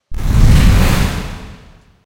PixelPerfectionCE/assets/minecraft/sounds/mob/enderdragon/wings1.ogg at mc116
wings1.ogg